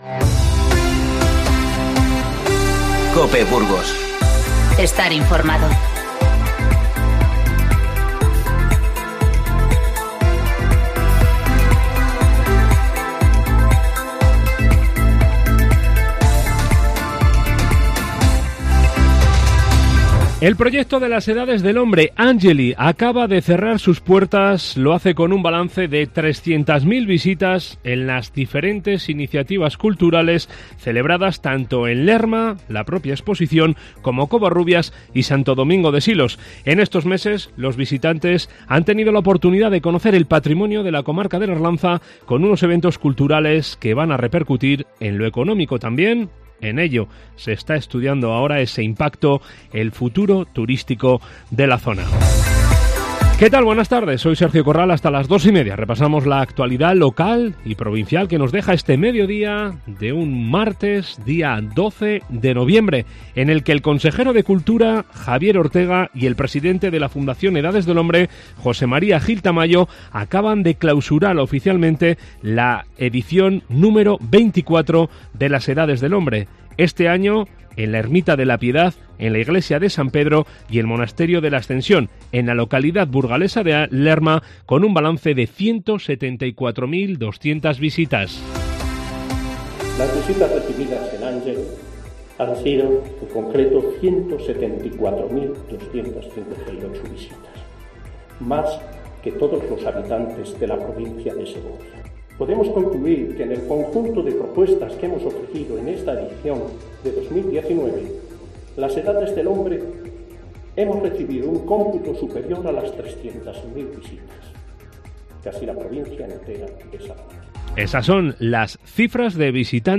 INFORMATIVO Mediodía 12-11-19